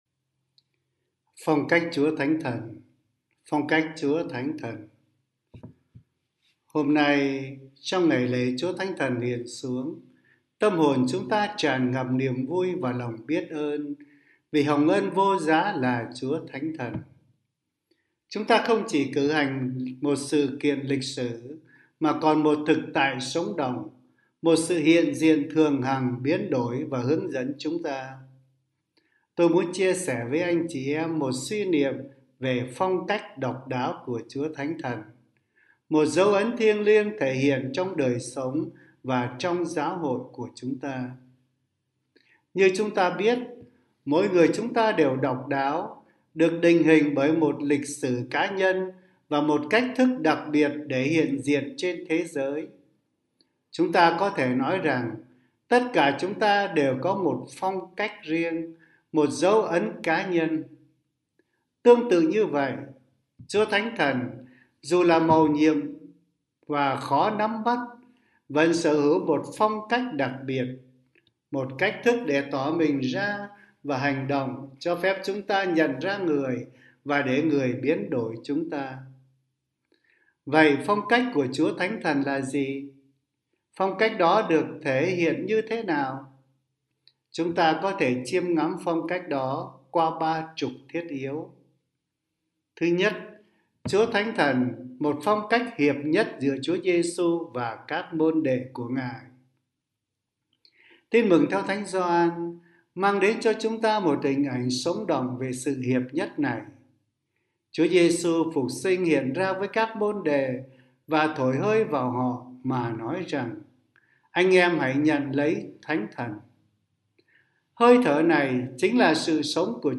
Suy niệm Chúa Nhật
Nữ miền Nam 🎙 Nam miền Nam